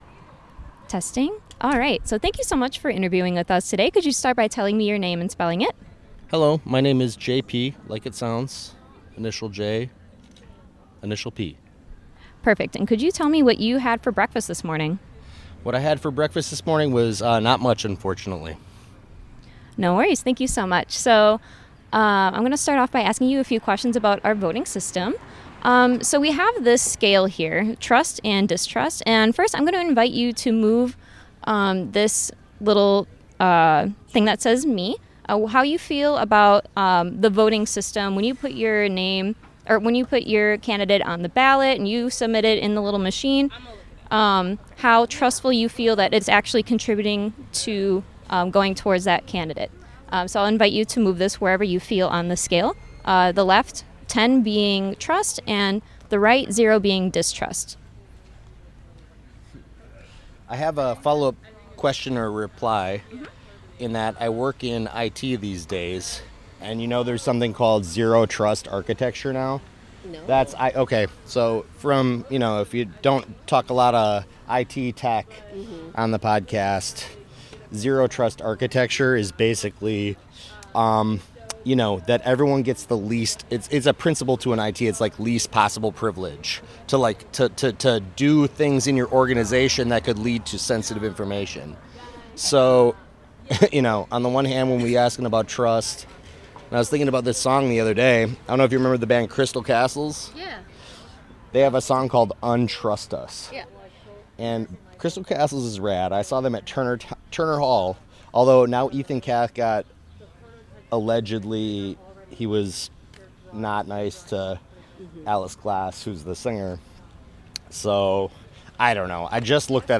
Location Cactus Club